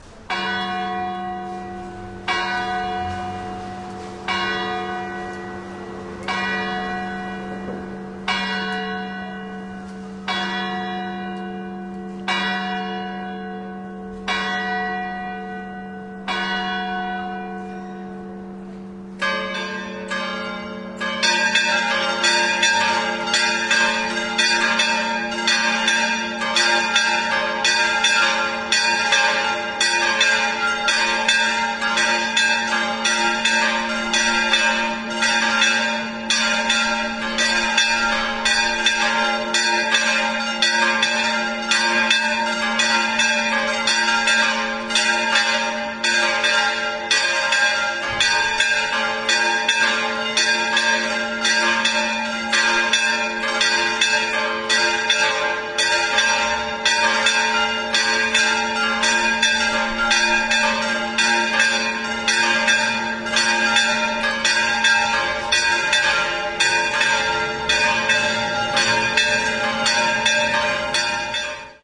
描述：钟声响起。奥林巴斯LS10内部麦克风。
Tag: 教堂 现场录音 敲打 塞维利亚 南西班牙